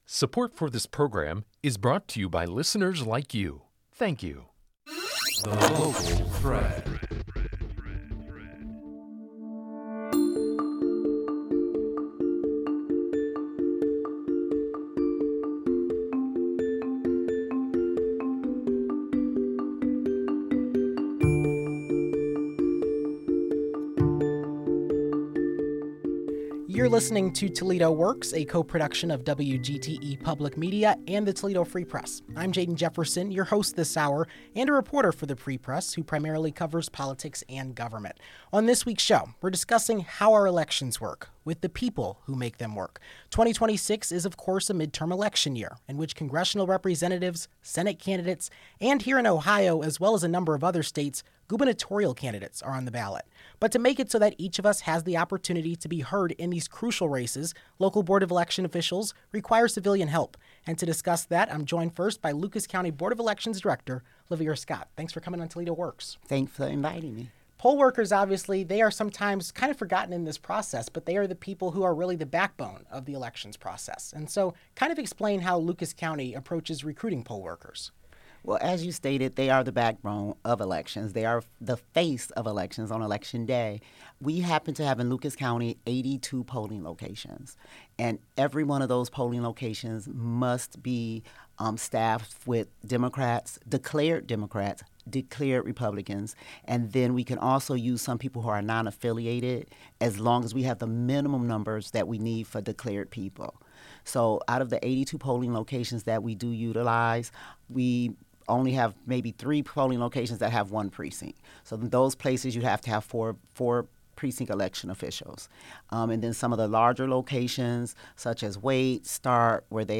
It's a conversation focusing on the role everyday people people play in making our elections happen, as well as the challenges facing local elections officials.